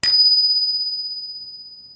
question_markTermékkör Pengetős csengő
Mini csengő, erős pengető mechanikával
Egyszerű mechanika, erős, hosszú lecsengésű hang